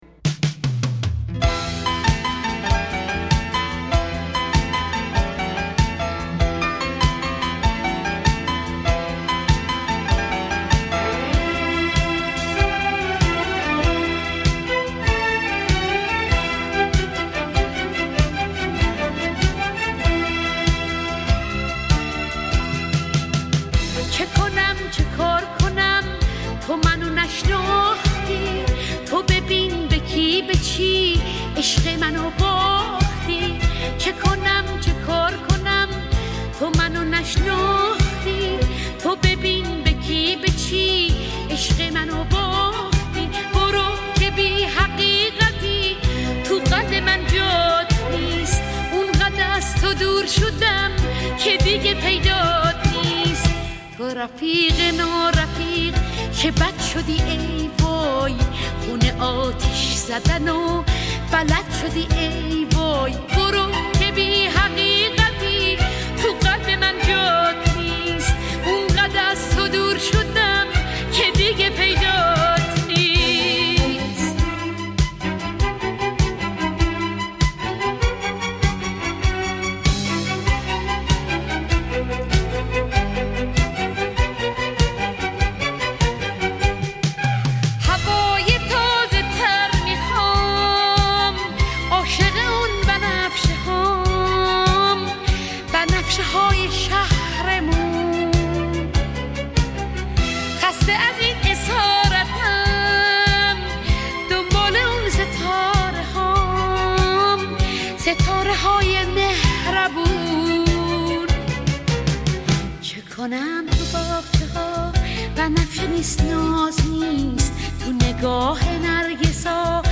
پاپ ایرانی قدیمی